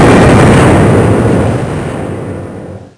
1 channel
owlboom.mp3